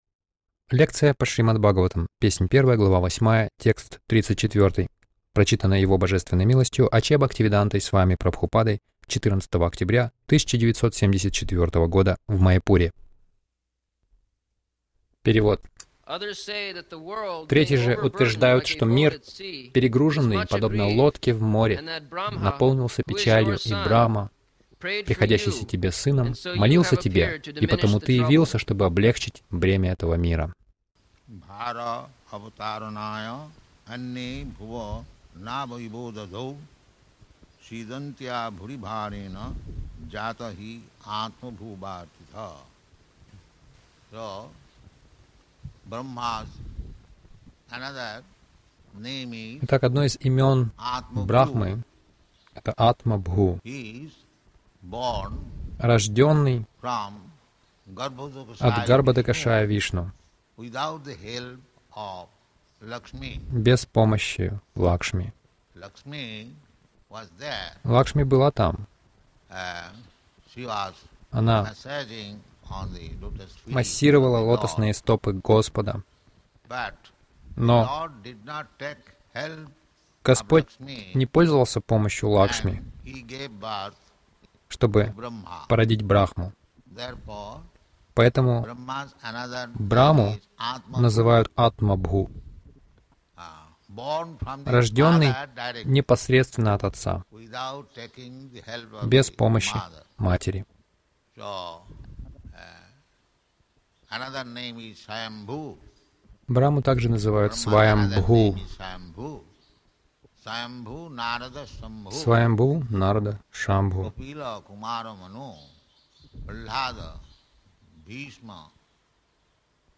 Милость Прабхупады Аудиолекции и книги 14.10.1974 Шримад Бхагаватам | Маяпур ШБ 01.08.34 — Кришна явился как Махамантра Загрузка...